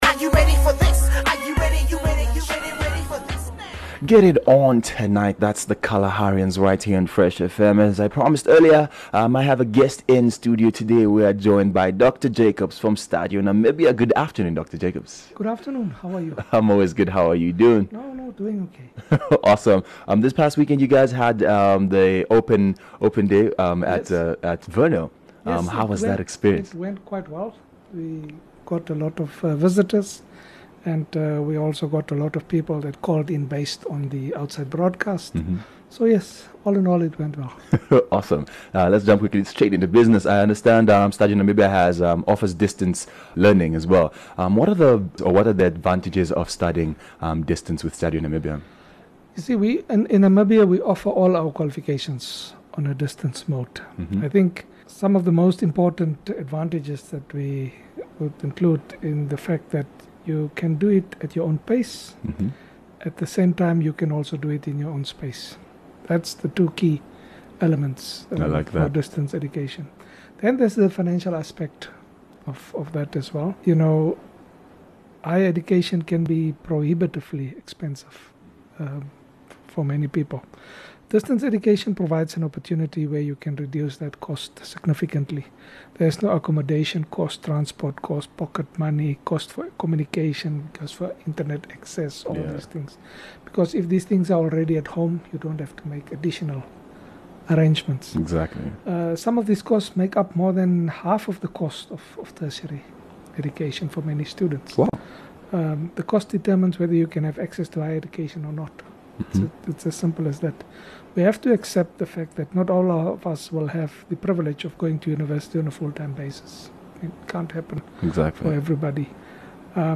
View Promo Continue Install Fresh Lunch 15 Nov Stadio Namibia Interview